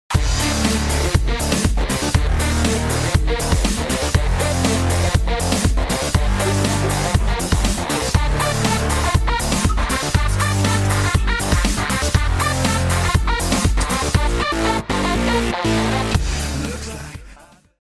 Techno Cinema Remix